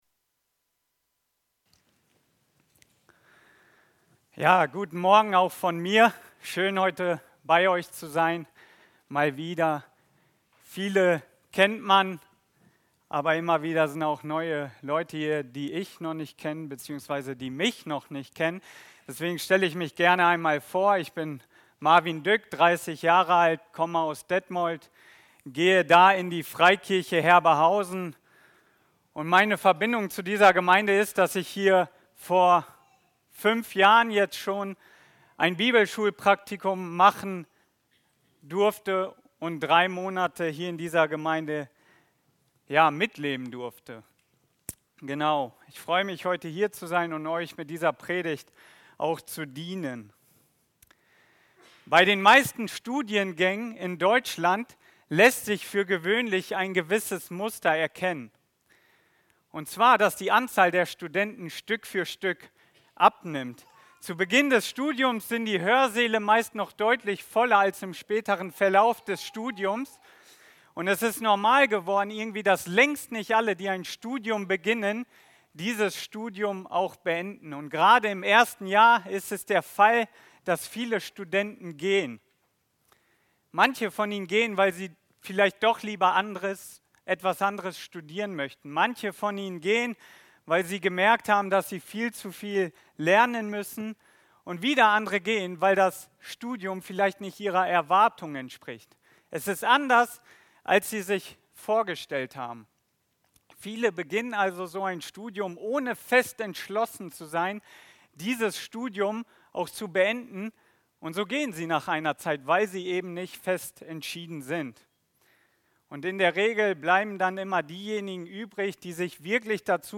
Predigten FCGL Gehen oder Bleiben?!